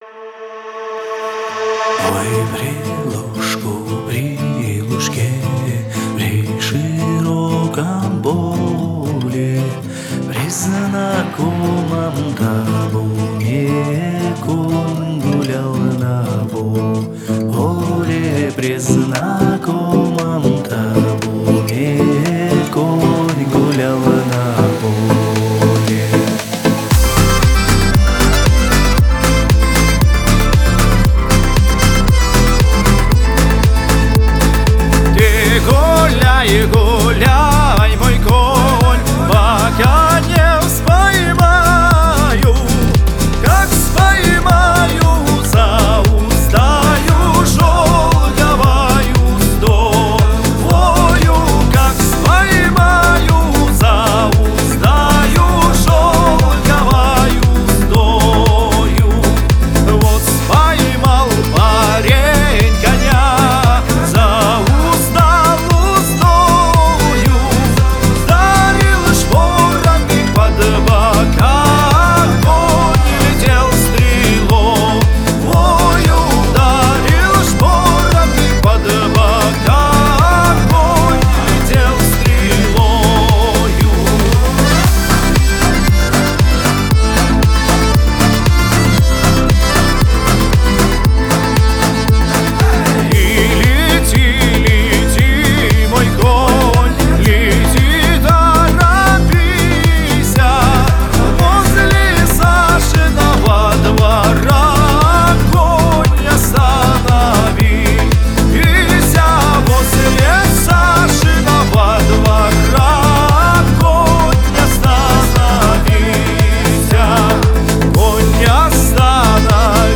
Русские народные песни